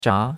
zha2.mp3